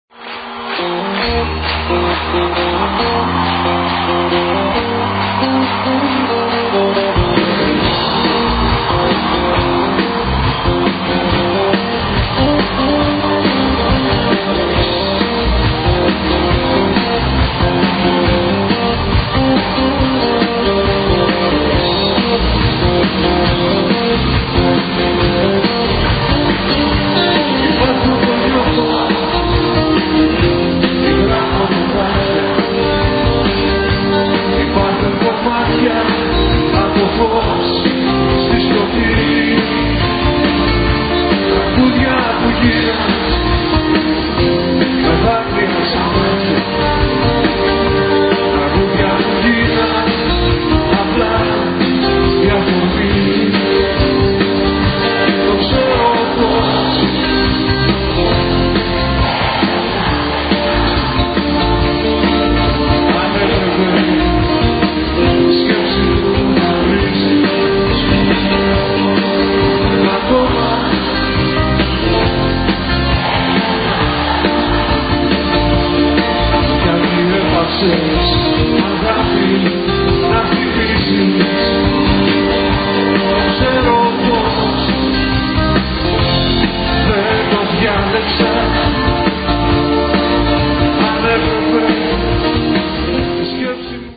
Rock Greek music.